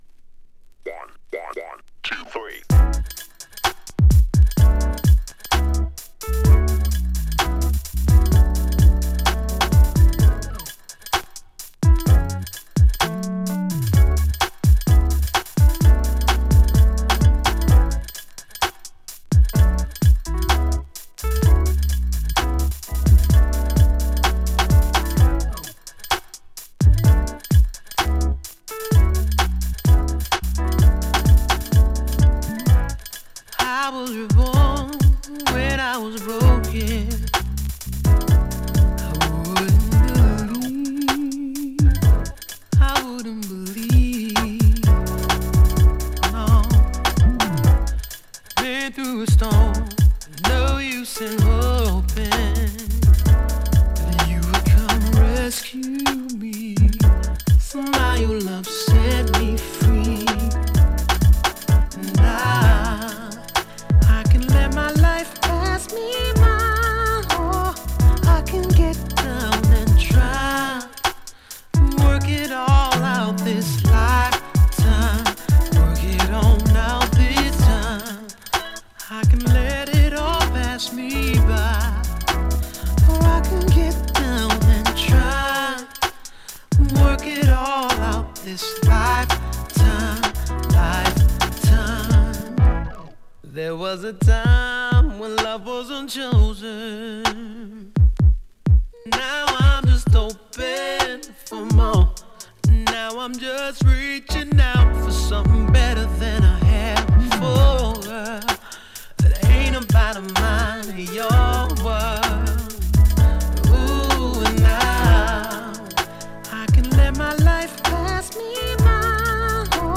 3. > JAZZY/NEO SOUL